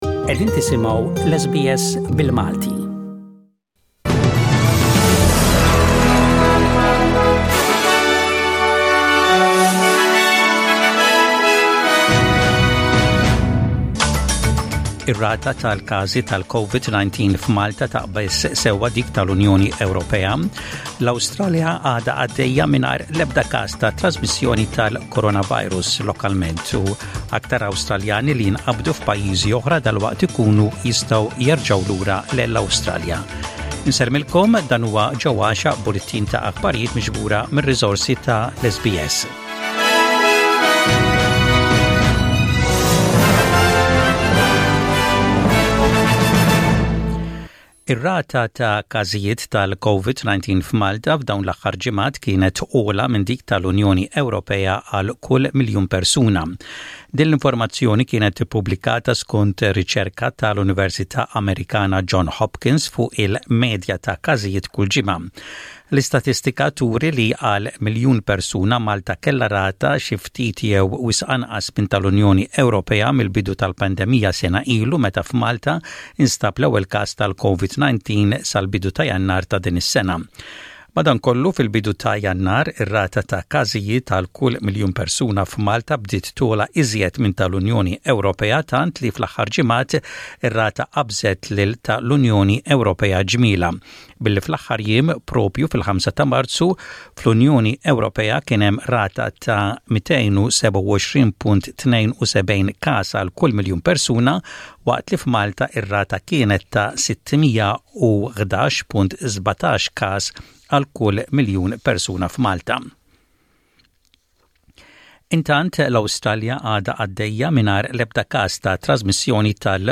SBS Radio | Aħbarijiet bil-Malti: 09/03/21